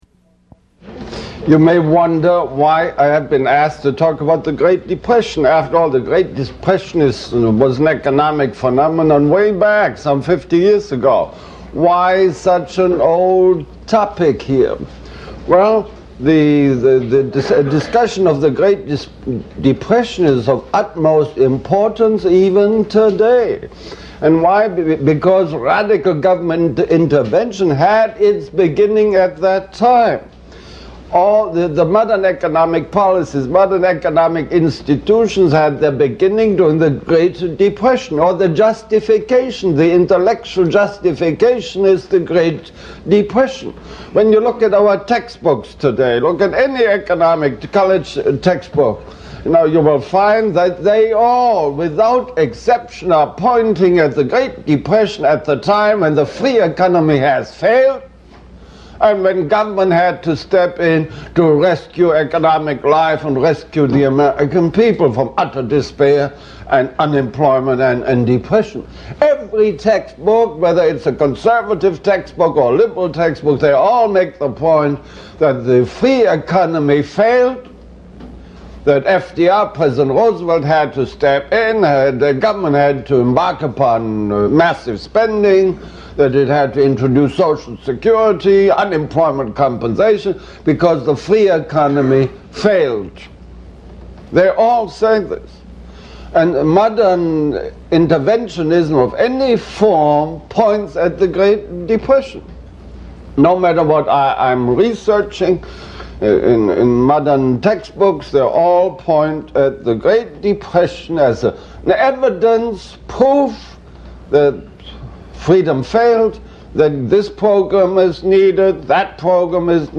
lecturing to students about the Great Depression on February 29, 1988.